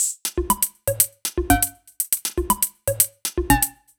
Index of /musicradar/french-house-chillout-samples/120bpm/Beats
FHC_BeatD_120-02_Tops.wav